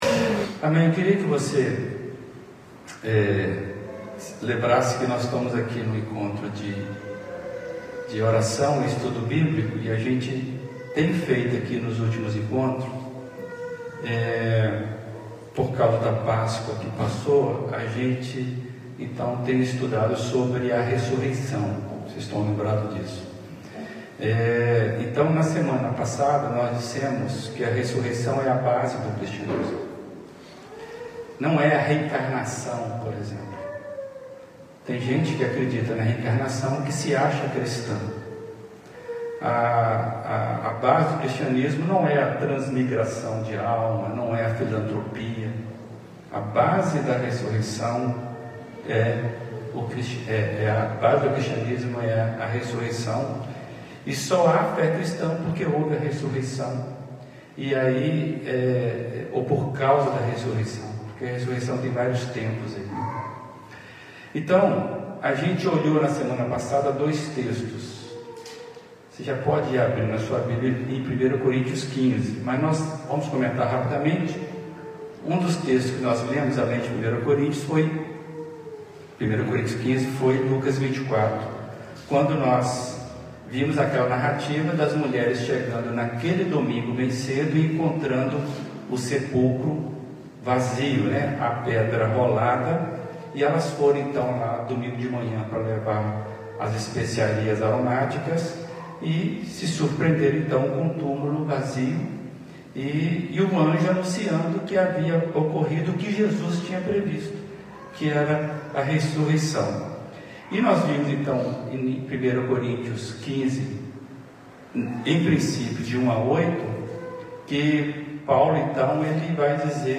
Mensagem apresentada
na Primeira Igreja Batista de Brusque.